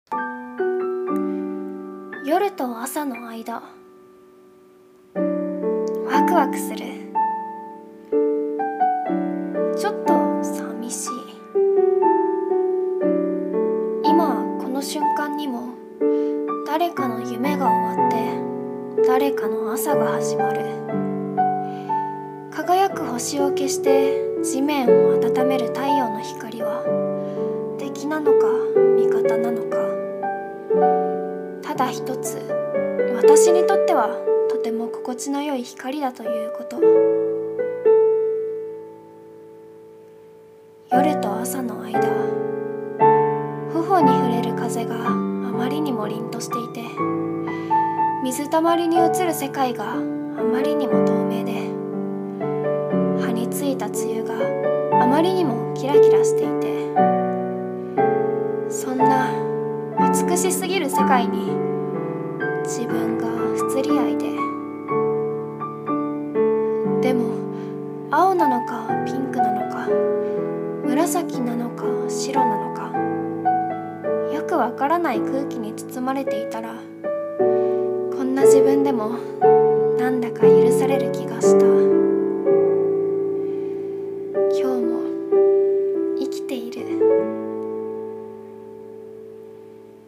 一人声劇】薄明 はくめい